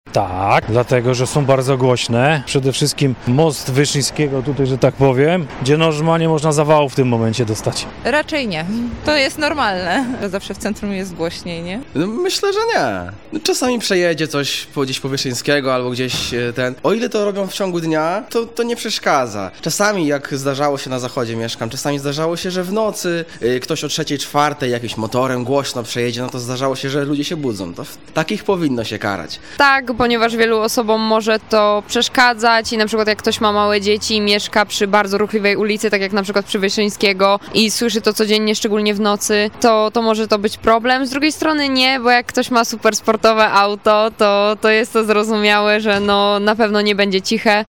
Twoje Radio zapytało również mieszkańców Stargardu, czy ich zdaniem za zbyt głośne pojazdy powinno się karać: